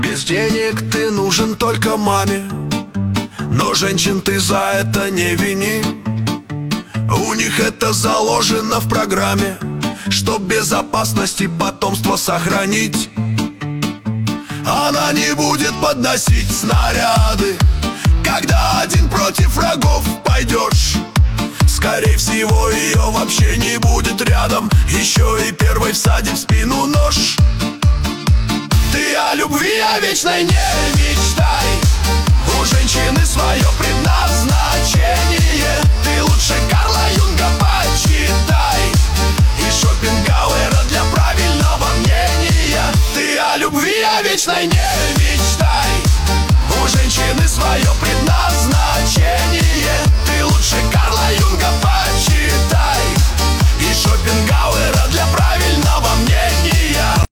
нейросеть ии